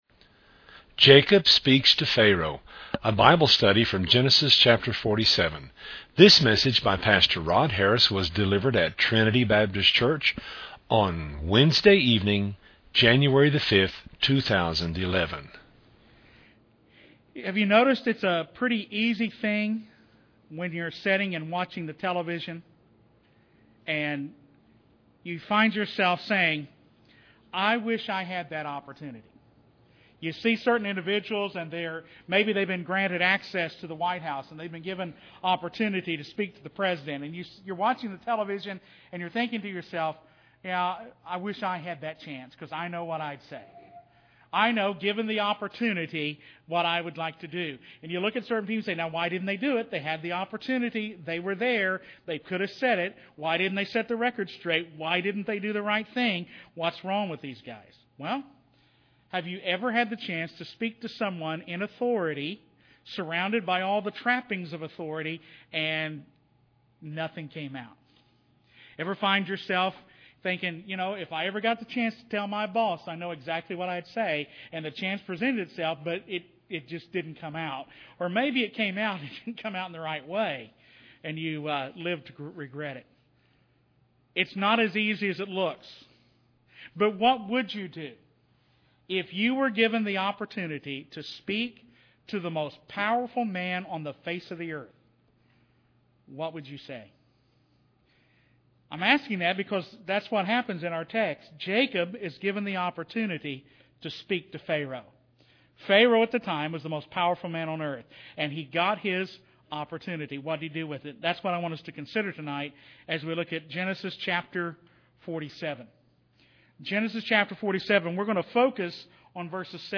A Bible study from Genesis 47.